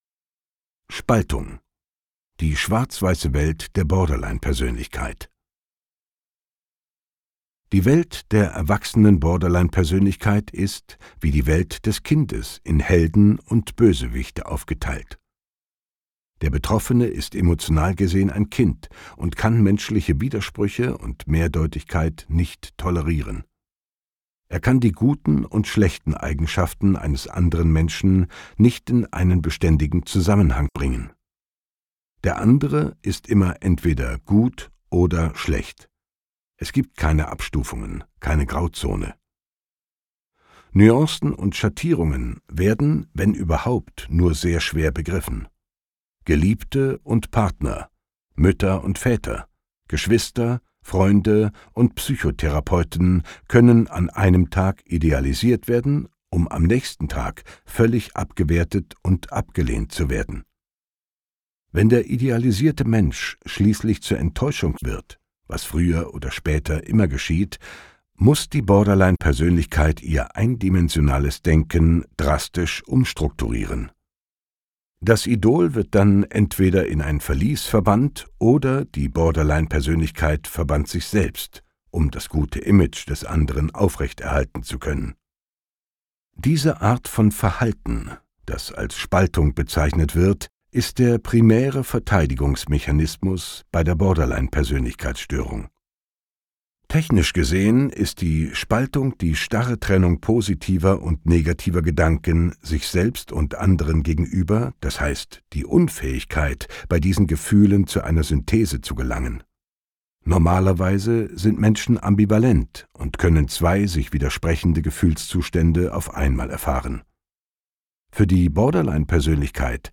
Das Standardwerk zum Borderline-Syndrom - erstmals als Hörbuch!Menschen mit einer Borderline-Persönlichkeit leiden unter extremen Stimmungsschwankungen und der ständigen Angst, verlassen zu werden.